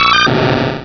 pokeemerald / sound / direct_sound_samples / cries / totodile.aif
totodile.aif